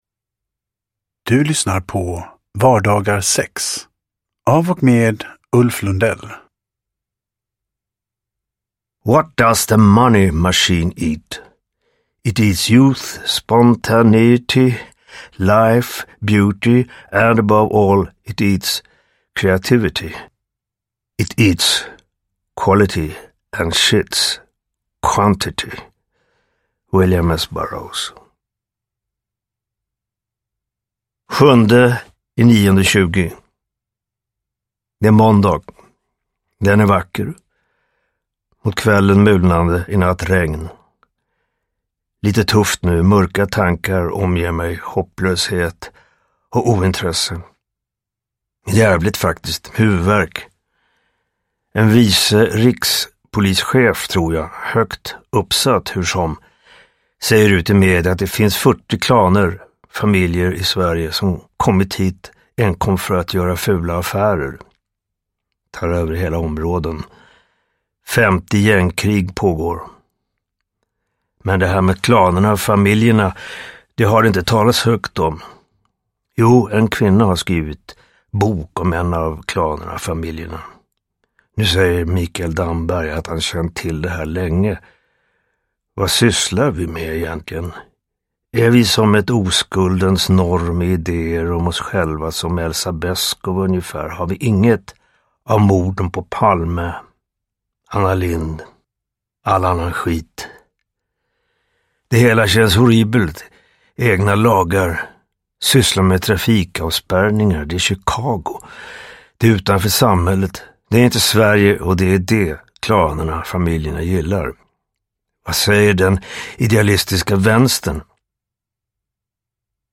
Vardagar 6 – Ljudbok – Laddas ner
Uppläsare: Ulf Lundell